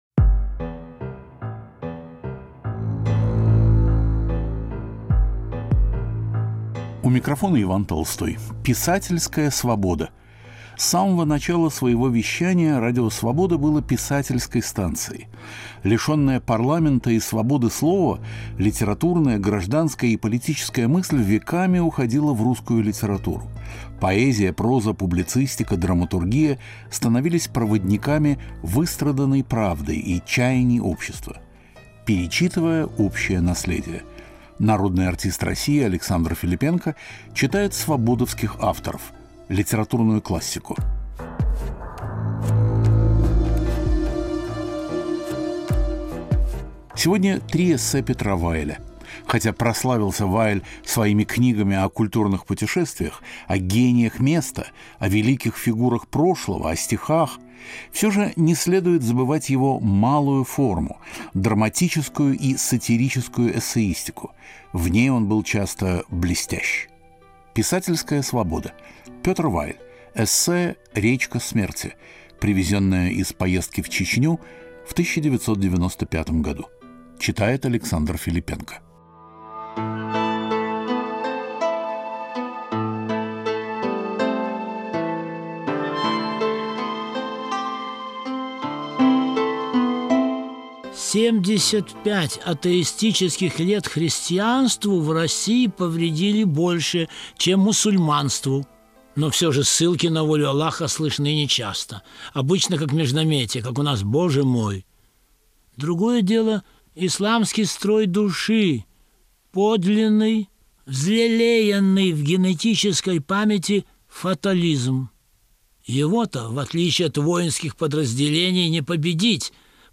Александр Филиппенко читает эссе Петра Вайля